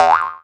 VEC3 Percussion 022.wav